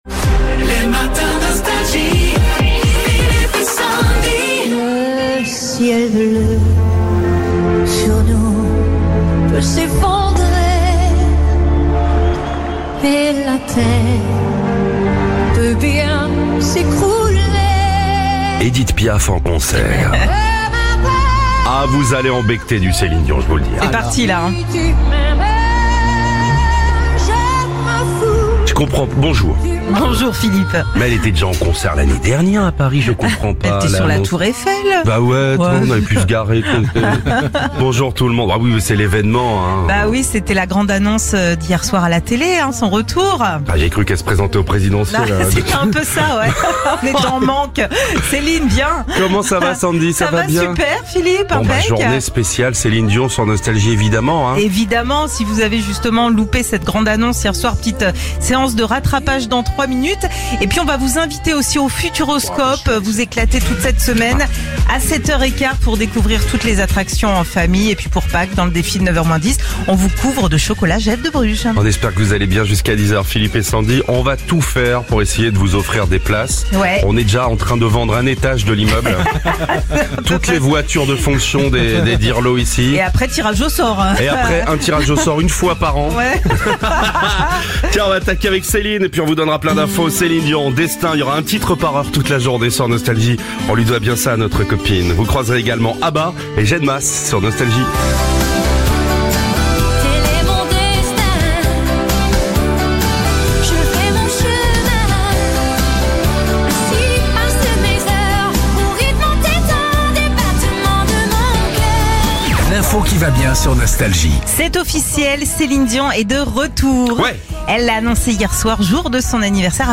Au programme : Bonne humeur et tous les tubes 80 !